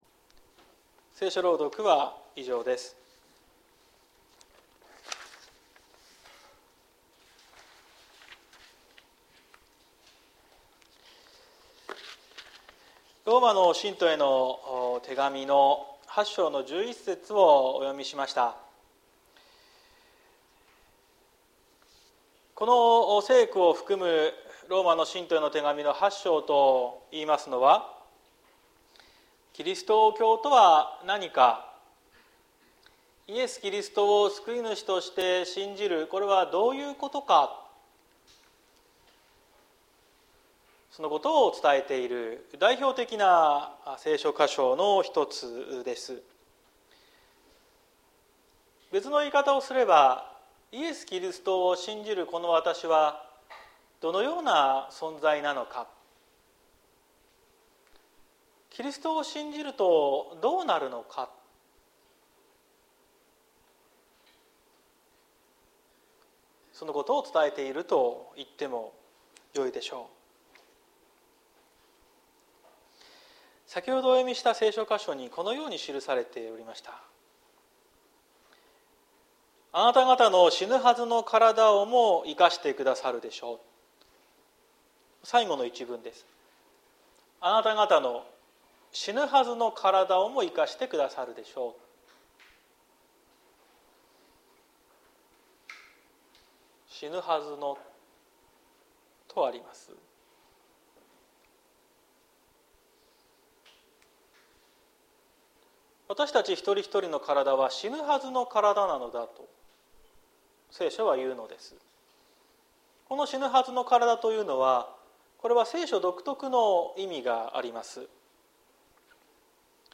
2023年08月27日朝の礼拝「生かされて生きる」綱島教会
説教アーカイブ。